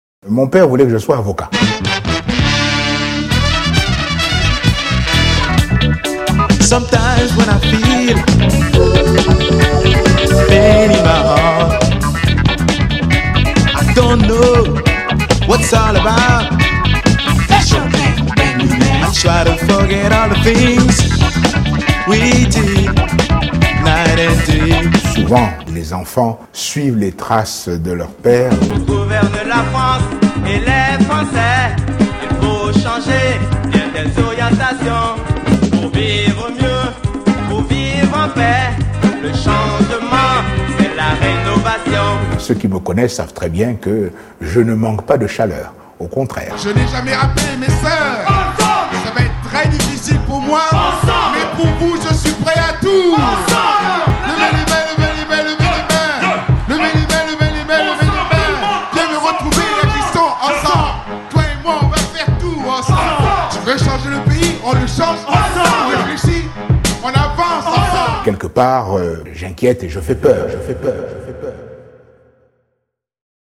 album funky